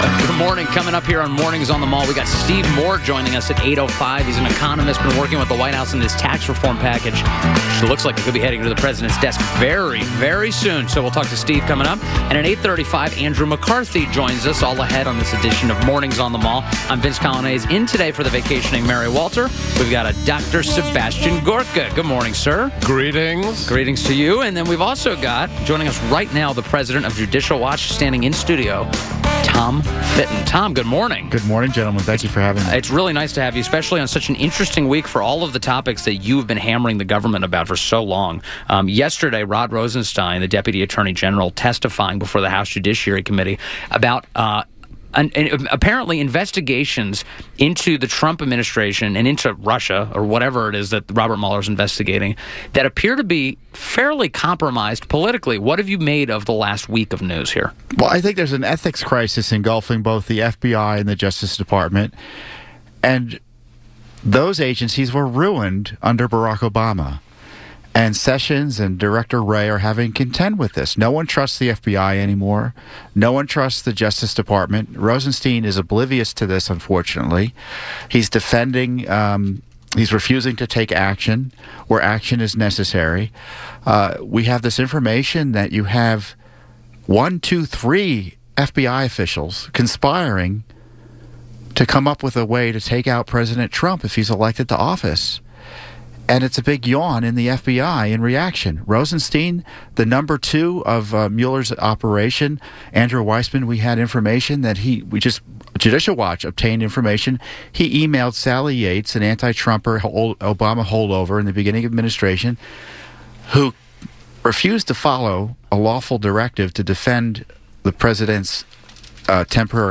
WMAL Interview - TOM FITTON - 12.14.17